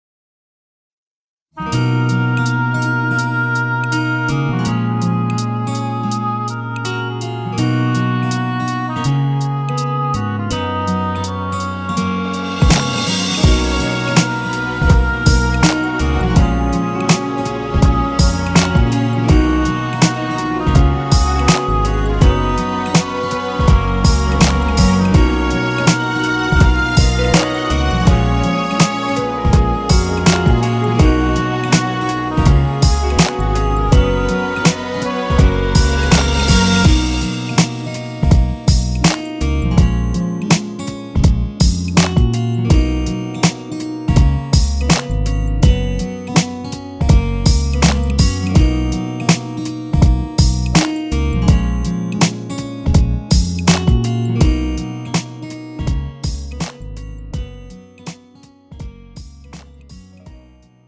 MR 고음질 반주 다운로드. 축가, 웨딩, 결혼식 MR. 원하는 MR 즉시 다운로드 가능.